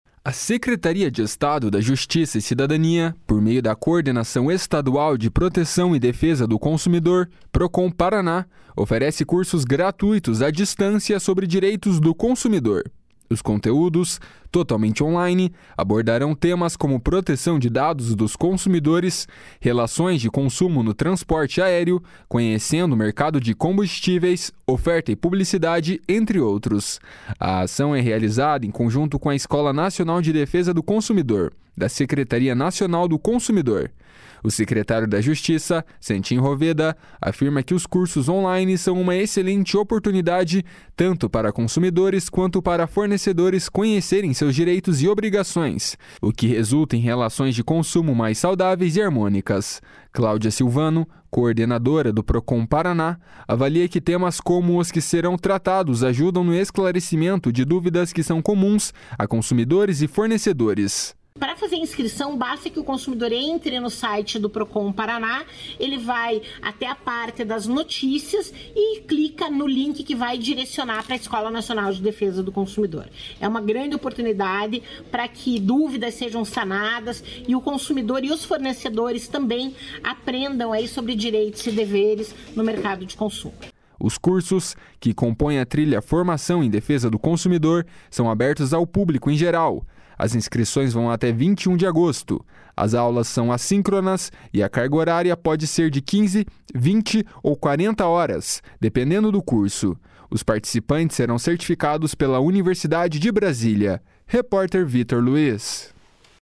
// SONORA CLAUDIA SILVANO //